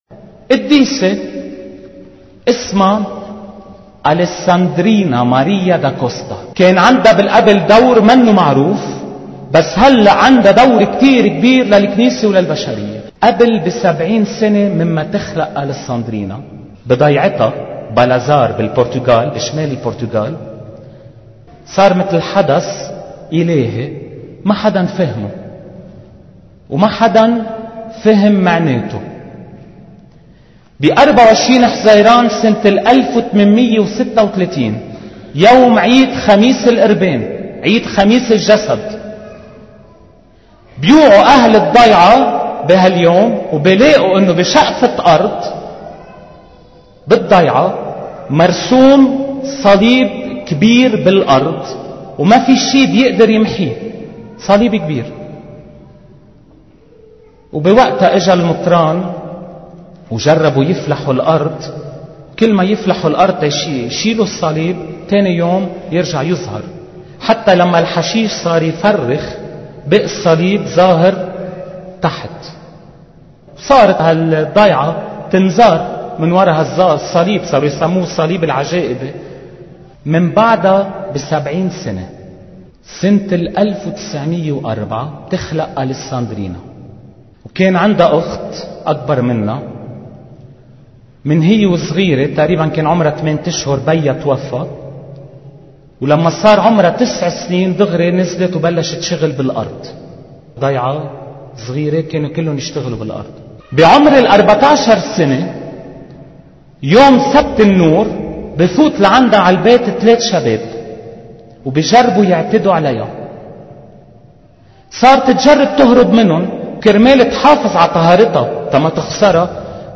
عظة